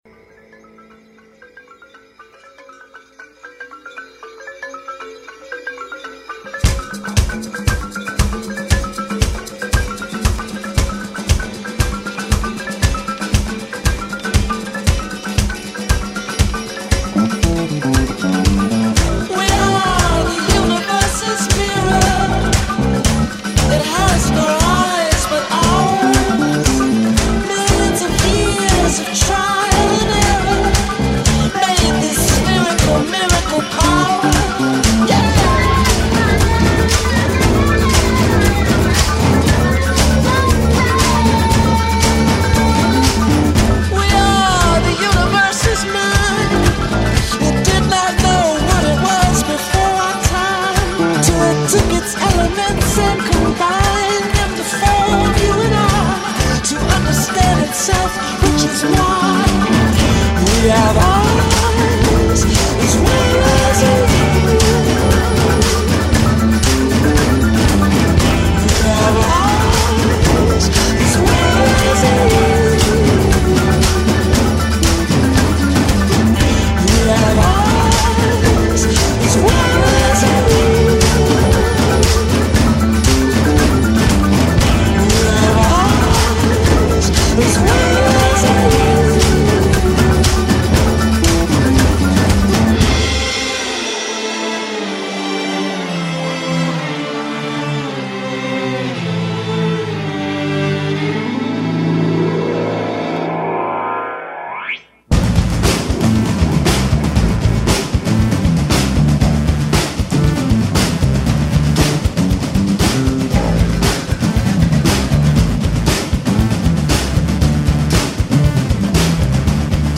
melodic, multi-layered, psychedelic rock quartet